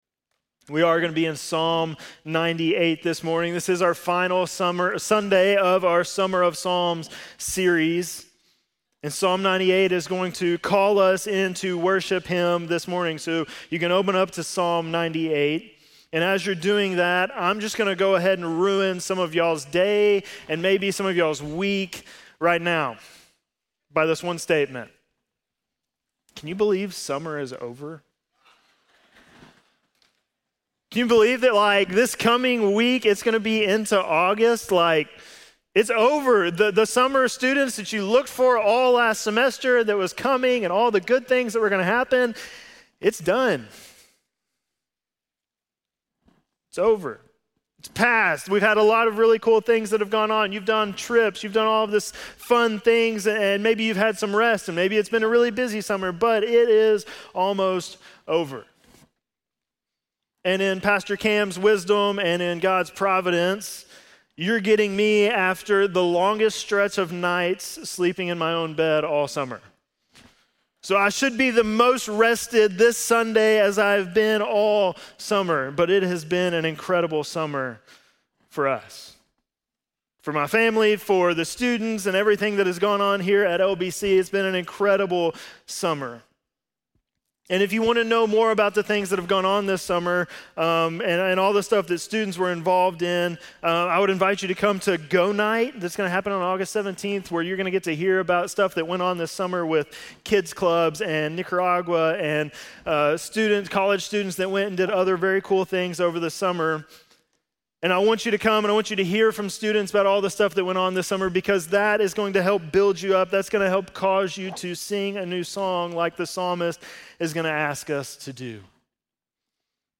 7.27-sermon.mp3